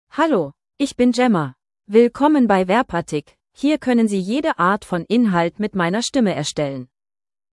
GemmaFemale German AI voice
Gemma is a female AI voice for German (Germany).
Voice sample
Listen to Gemma's female German voice.
Gemma delivers clear pronunciation with authentic Germany German intonation, making your content sound professionally produced.